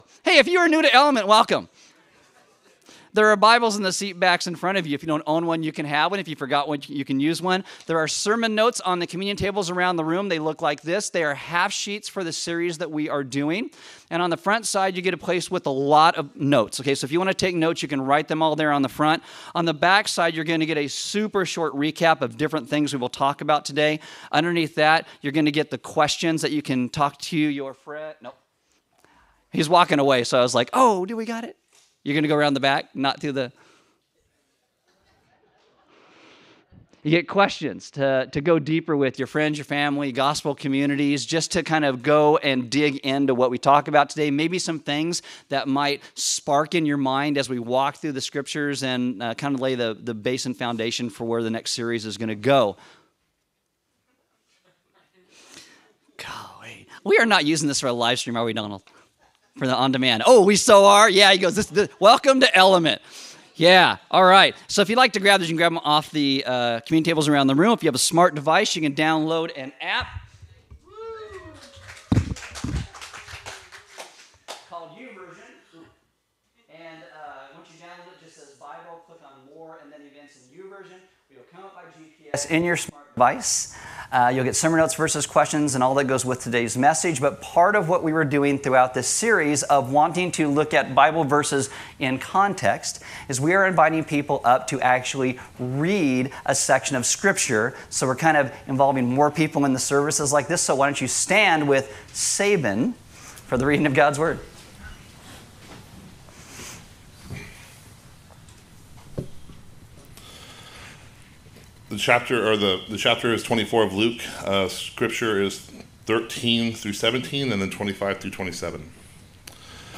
Download Sermon Notes Facebook Tweet Link Share Link Send Email